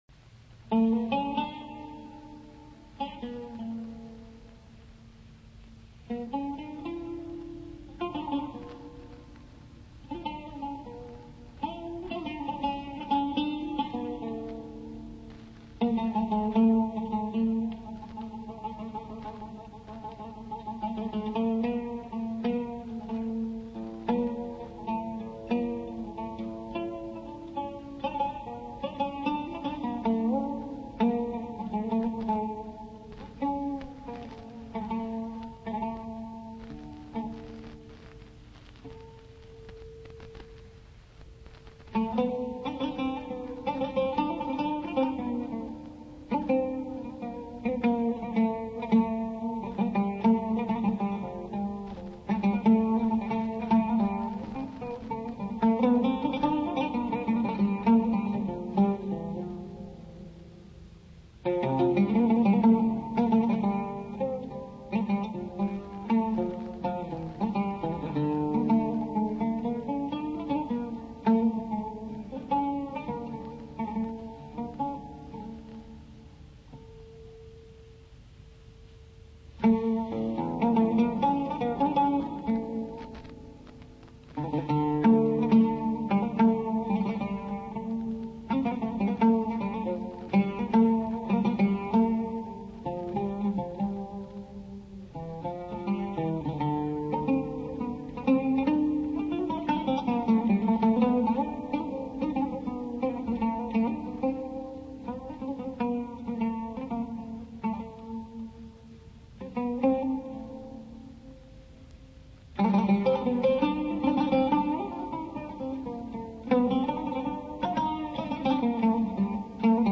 Ûd
Cinuçen Tanrıkorur – Taksim
cinucen_tanrikorur_ussak_taksim.mp3